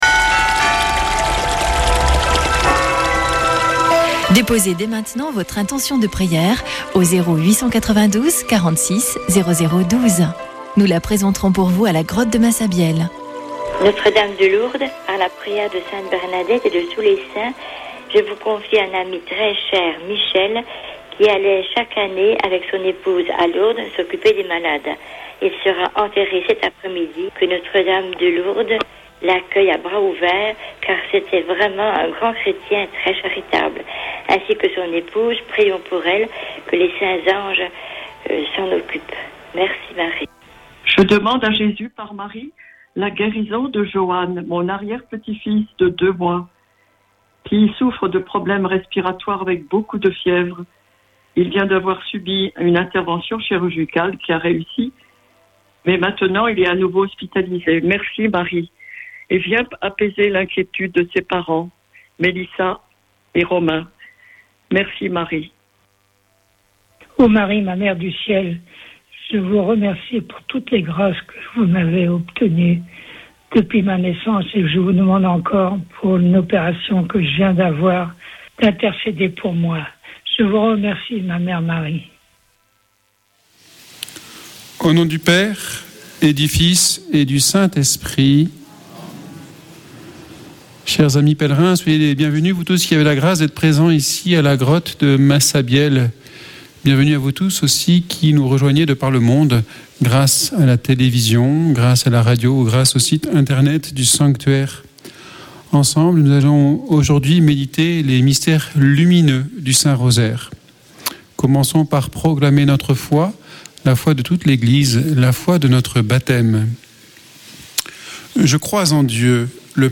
Accueil \ Emissions \ Foi \ Prière et Célébration \ Chapelet de Lourdes \ Chapelet de Lourdes du 27 nov.
Une émission présentée par Chapelains de Lourdes